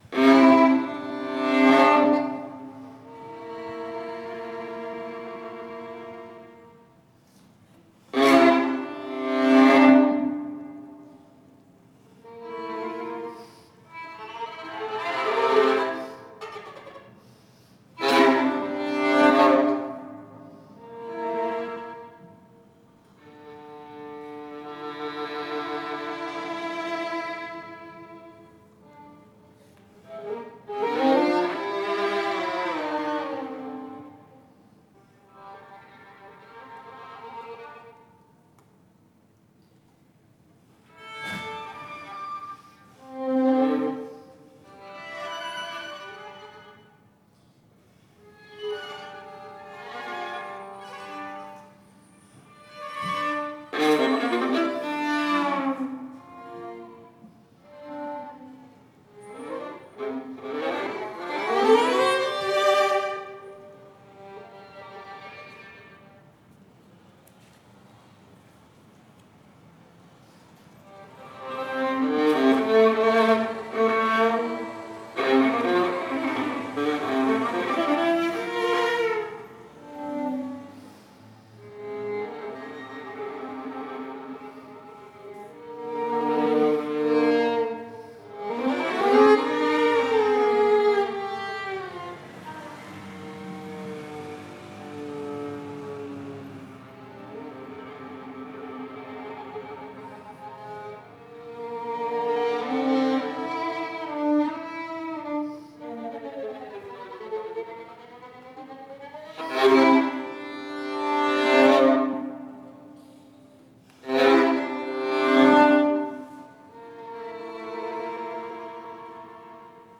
per violino e viola � / for violin and viola (2018)
violin
viola